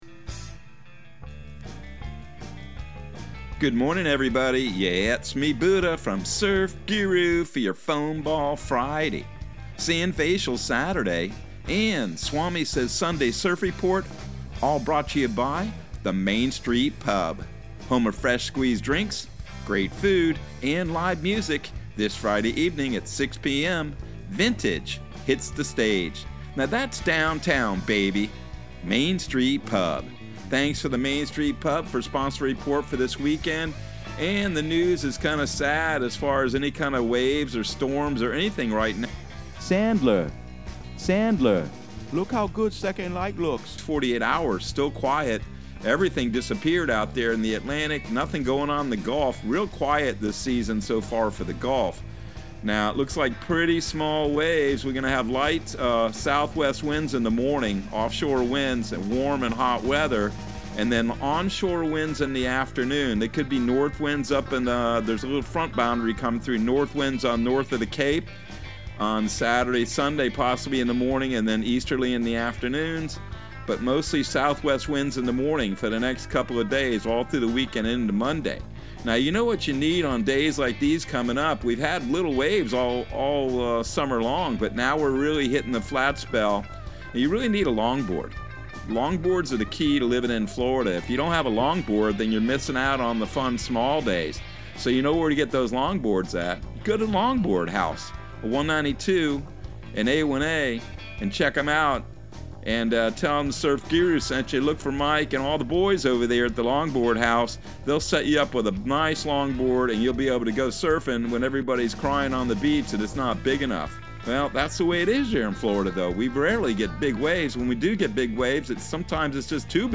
Surf Guru Surf Report and Forecast 08/12/2022 Audio surf report and surf forecast on August 12 for Central Florida and the Southeast.